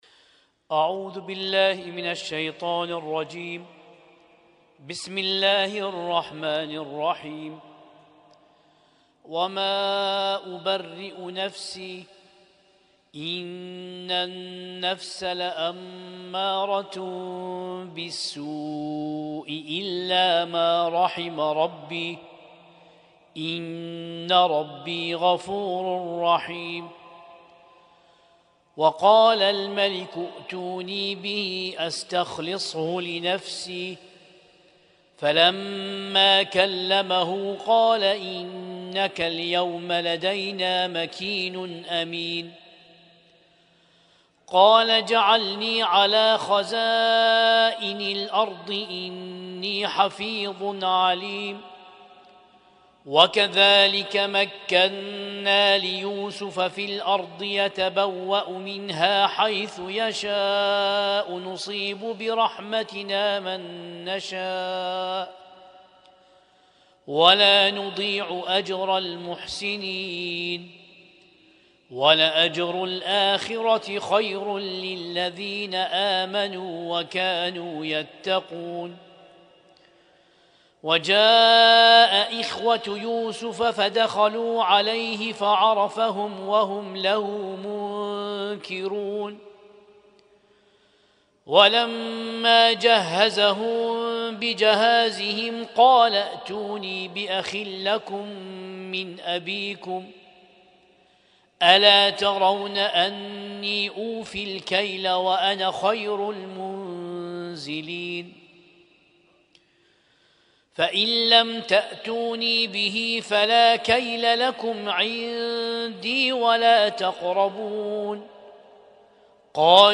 Husainyt Alnoor Rumaithiya Kuwait
القارئ